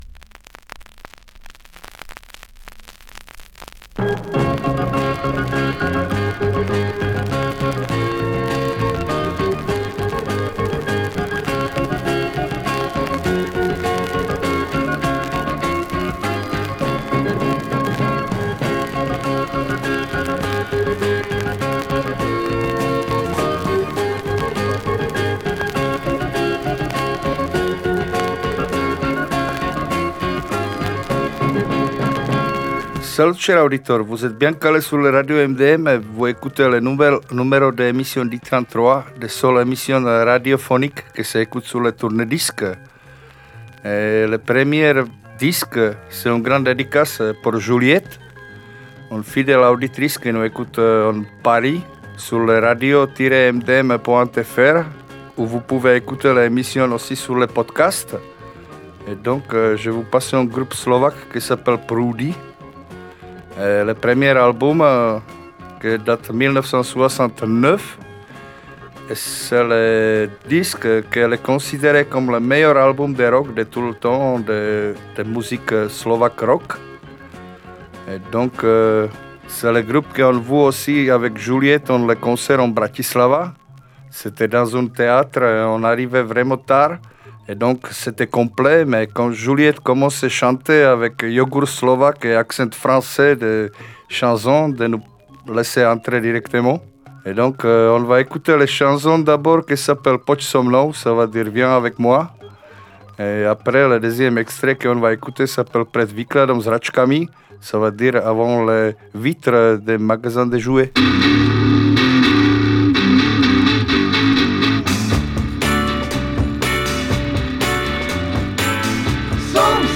La playlist rock